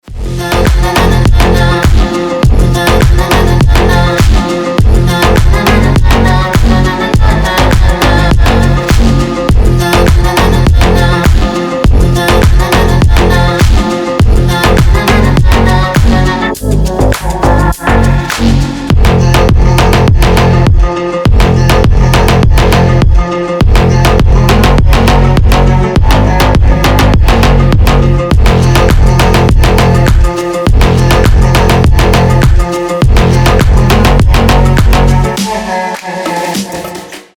• Качество: 320, Stereo
восточные мотивы
басы
Bass House
качающие
эхо
Стиль: bass house